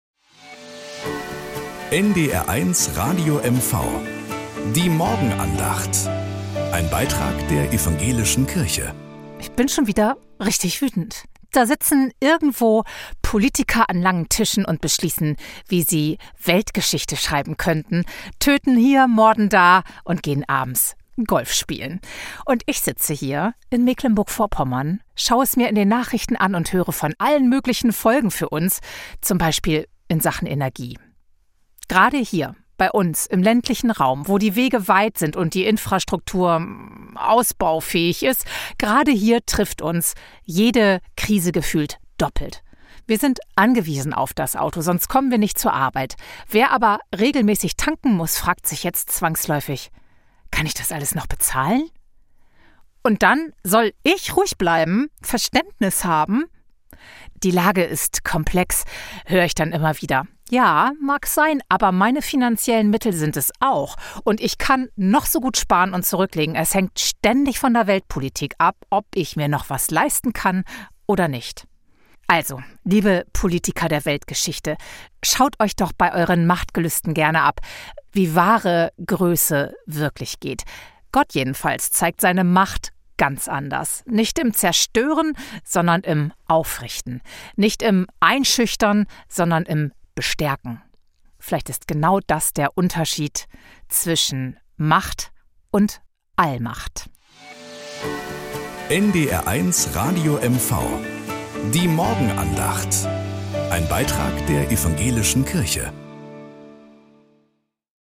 Morgenandacht.